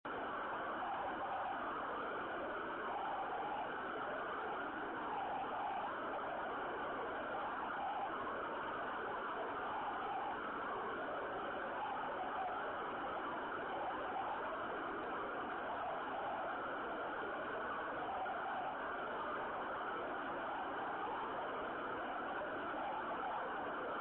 Pozorně si poslechněte, jak vypadají signály z pásma 24GHz, které přišly z Texasu odrazem od Měsíce. Charakteristický je jejich syčivý tón, vzniklý odrazy - vlnová délka je 1,5cm!